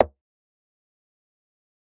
click-short.ogg